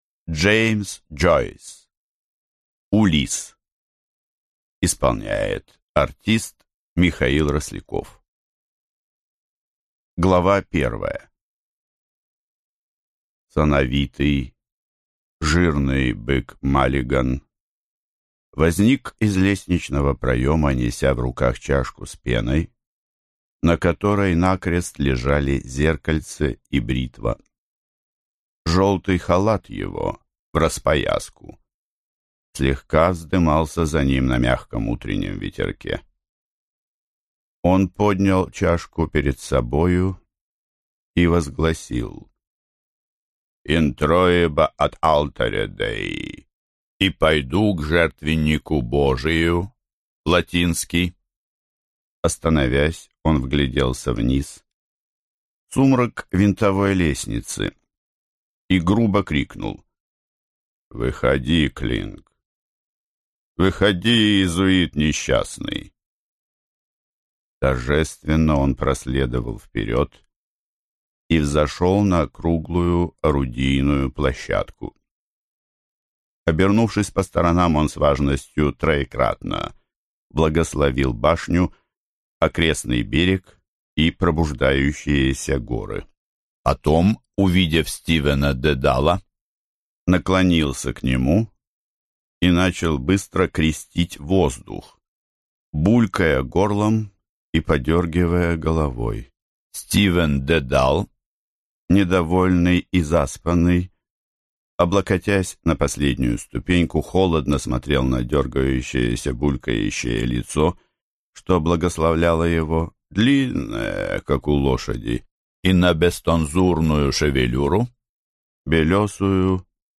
Аудиокнига Улисс | Библиотека аудиокниг